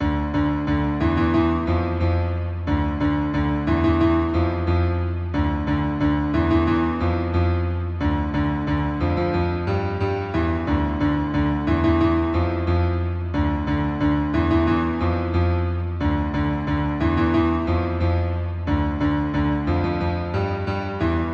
大钢琴循环播放
描述：Fl Studio 11三角钢琴
Tag: 90 bpm Hip Hop Loops Piano Loops 3.59 MB wav Key : Unknown